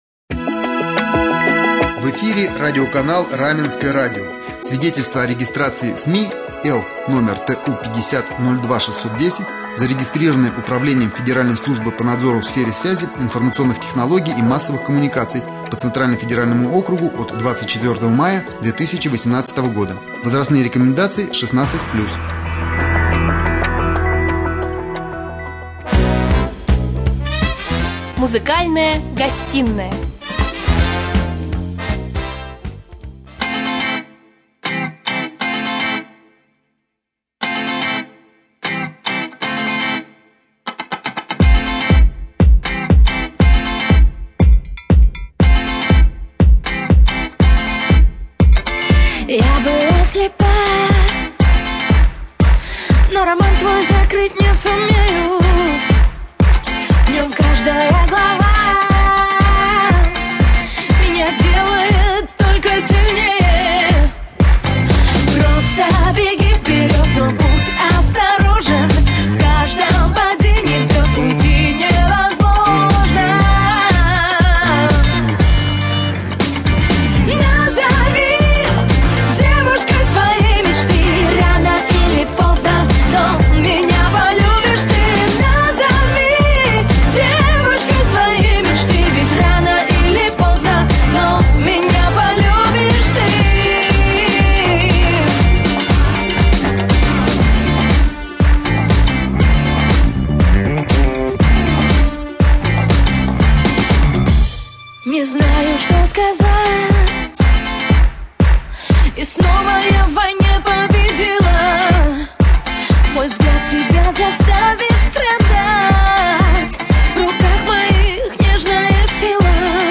Гостья студии